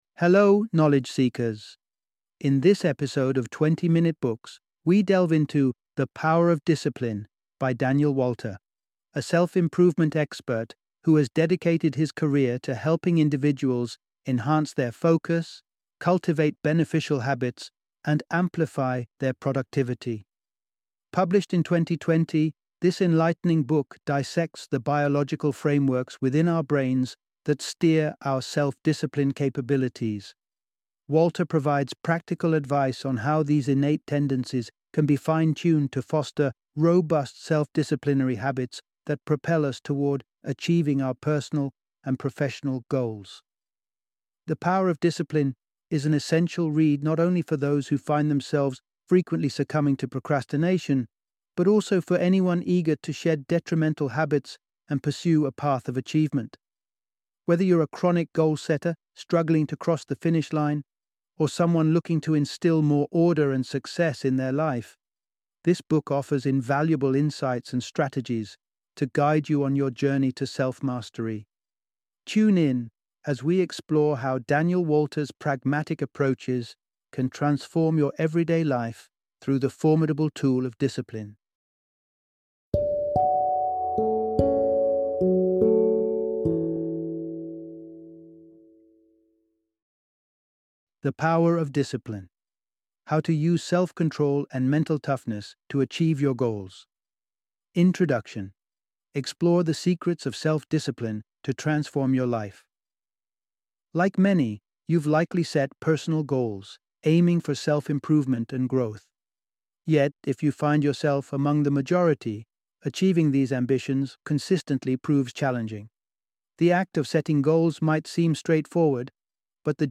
The Power of Discipline - Audiobook Summary